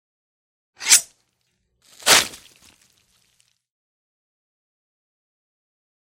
Звуки томагавка
Звук снятия скальпа томагавком (отрыв кожи с волосами)